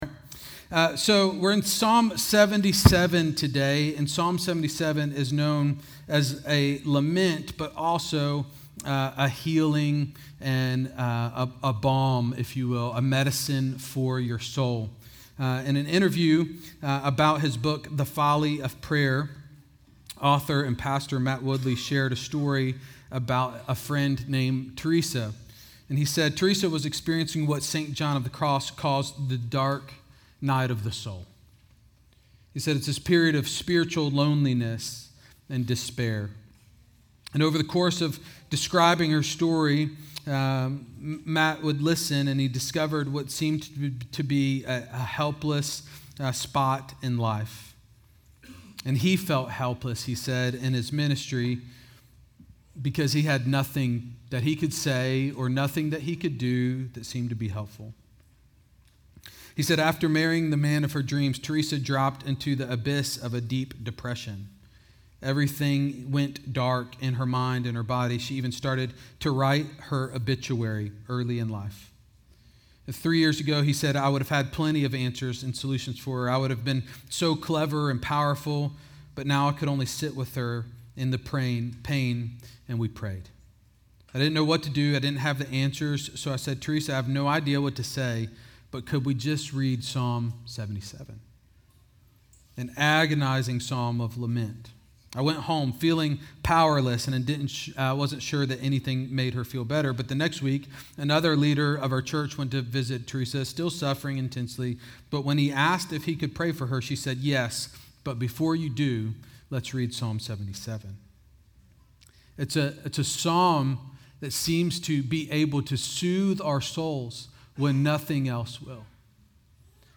Exchange Church Sermons